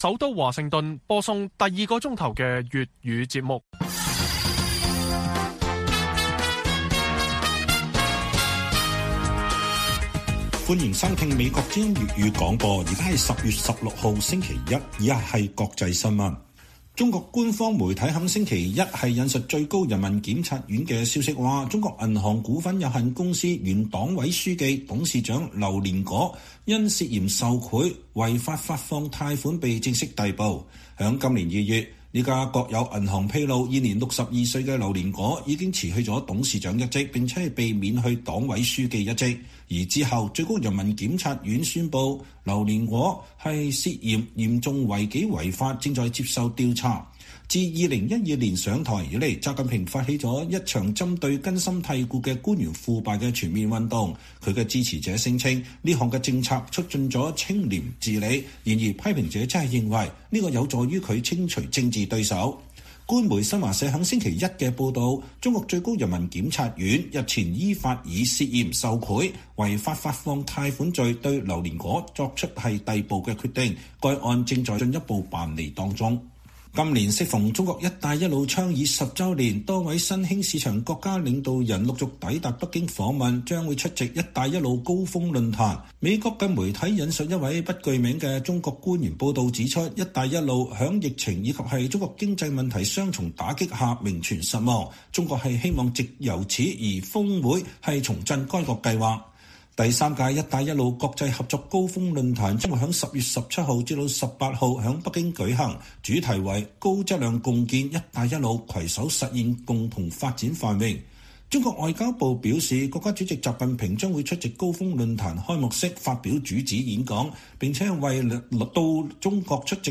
粵語新聞 晚上10-11點 ：新西蘭政黨輪替 警惕中國威脅成主要議題之一